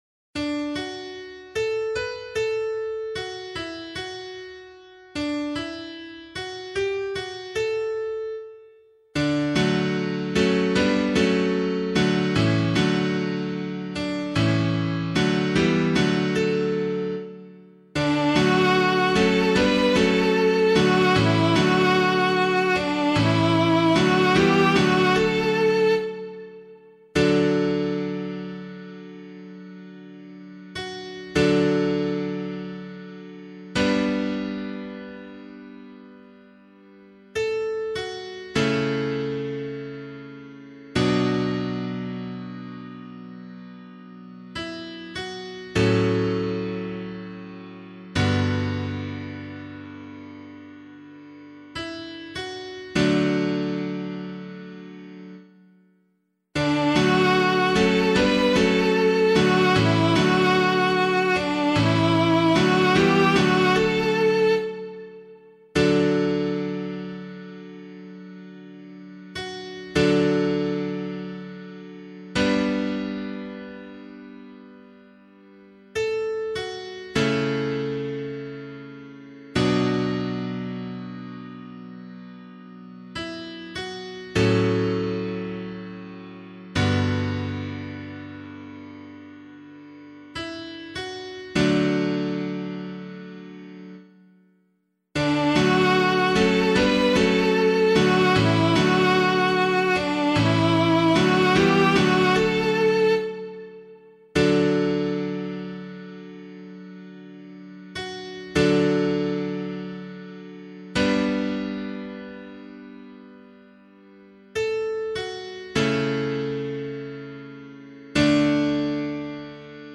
pianovocal
442 Immaculate Conception Psalm [APC - LiturgyShare + Meinrad 5] - piano.mp3